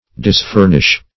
Search Result for " disfurnish" : The Collaborative International Dictionary of English v.0.48: Disfurnish \Dis*fur"nish\, v. t. [imp.